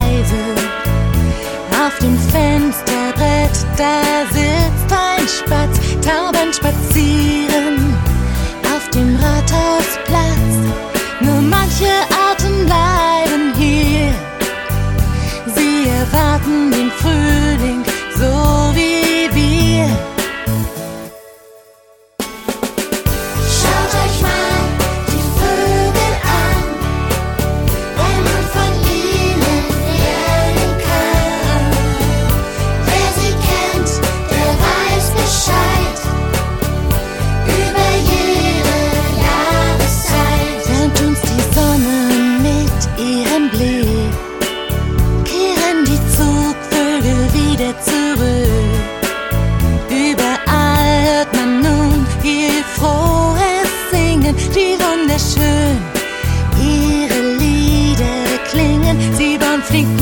• Sachgebiet: mp3 Kindermusik
1. 00109 Schaut euch mal die Voegel an Kinderlieder